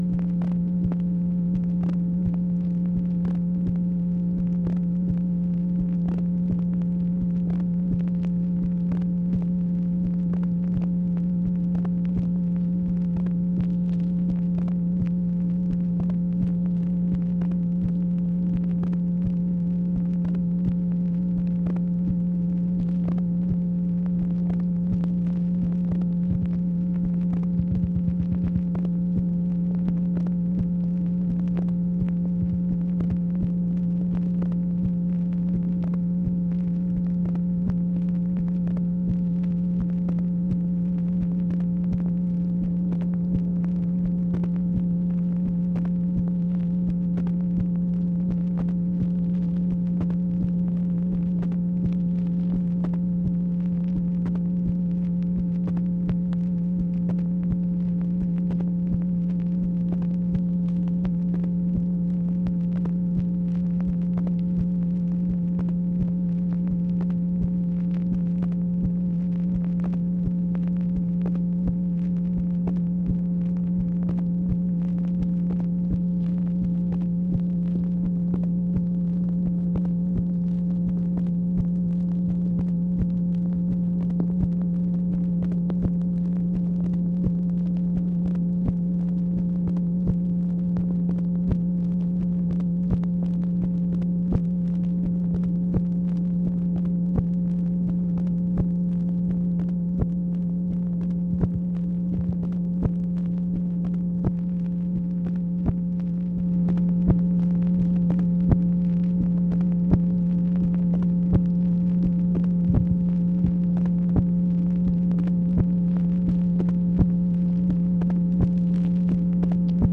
MACHINE NOISE, February 4, 1966
Secret White House Tapes | Lyndon B. Johnson Presidency